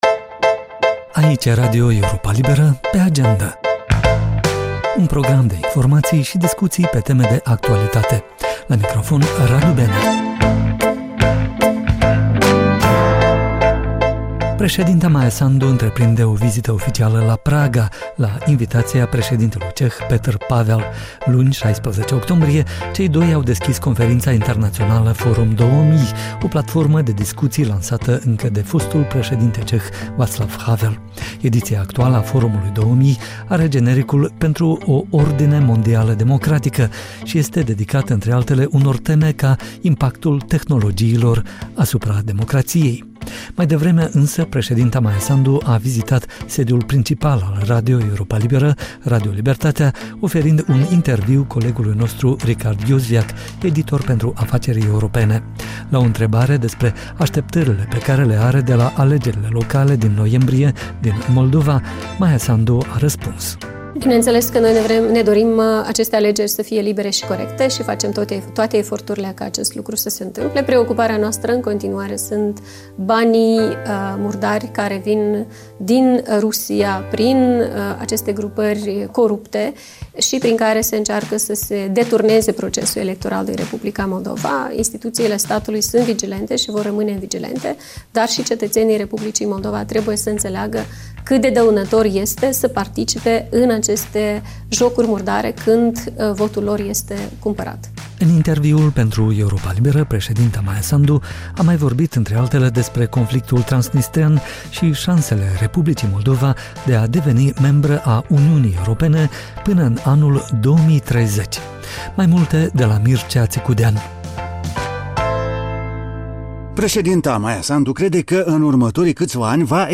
În acest episod, aflați ce a spus președinta Maia Sandu despre conflictul transnistrean și aderarea Moldovei la UE, în interviul oferit Europei Libere la Praga, dar și cum opoziția din Polonia ar putea să preia puterea după alegerile parlamentare de duminică.